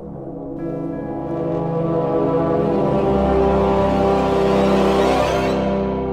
Category: Samsung Ringtones